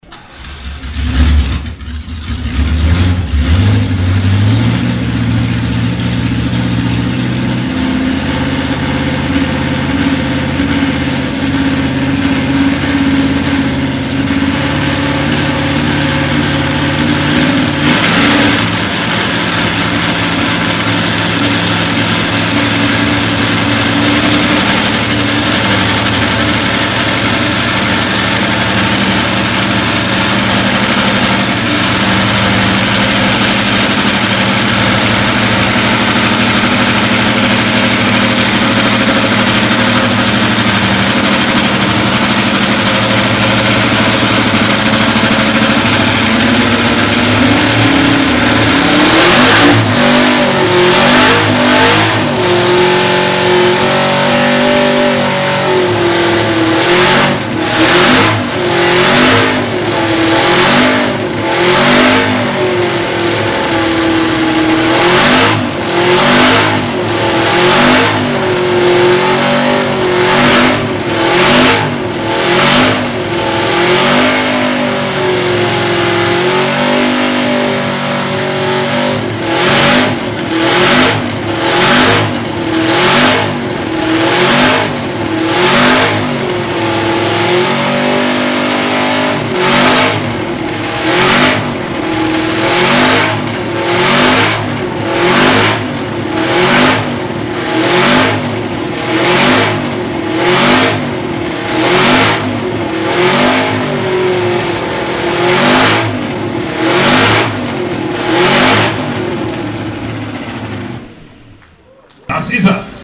Porsche 804 F1 (1962) an der Porsche Soundnacht 2016
Porsche-804-F1.mp3